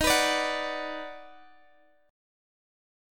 EbmM7#5 chord